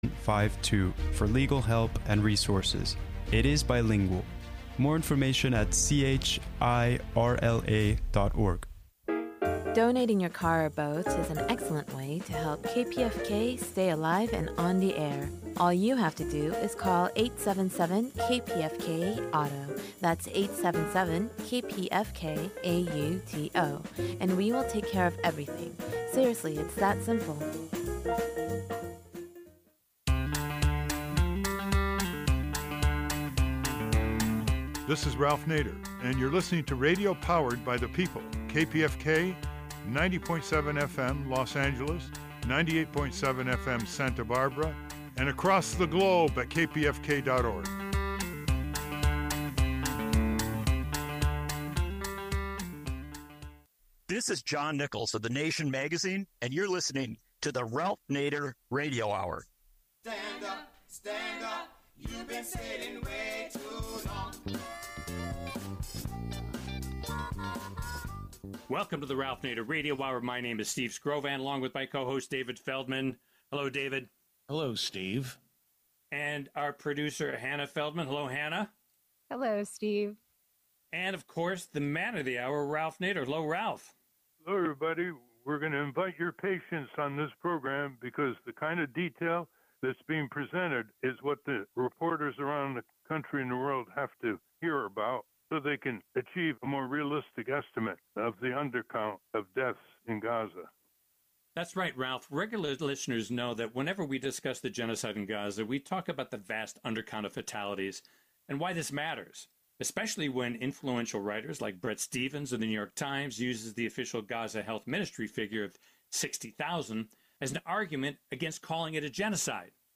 The Ralph Nader Radio Hour is a weekly talk show broadcast on the Pacifica Radio Network.